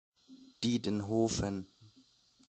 Thionville (French pronunciation: [tjɔ̃vil] ; Luxembourgish: Diddenuewen [ˈdidənuəvən]; German: Diedenhofen [ˈdiːdn̩ˌhoːfn̩]